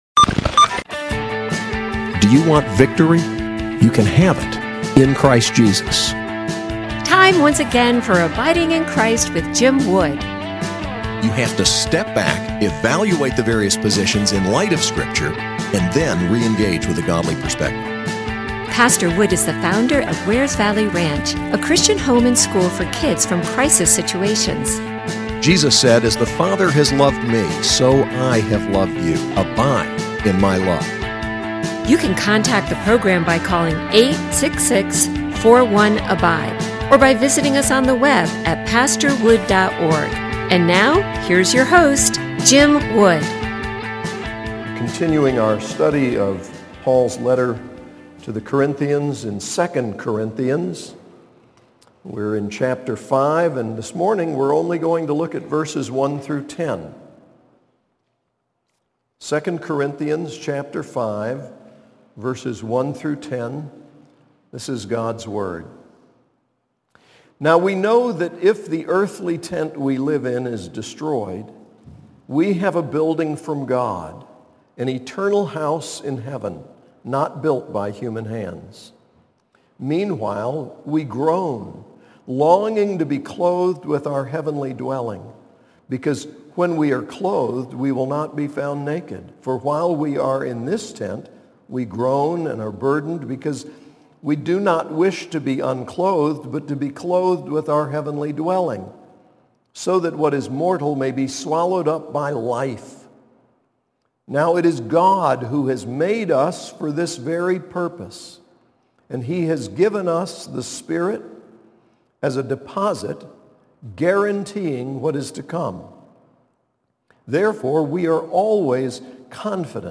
SAS Chapel: 2 Corinthians 5:1-10